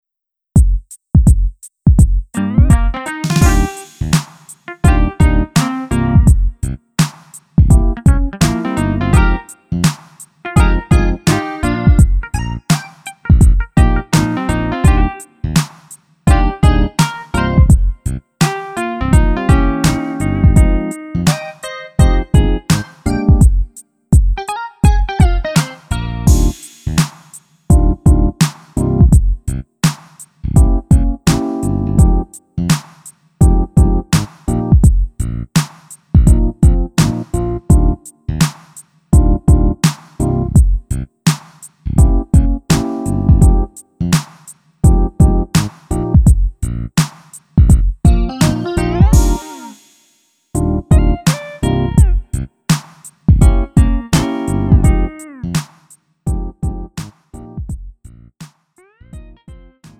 음정 -1키 3:55
장르 구분 Lite MR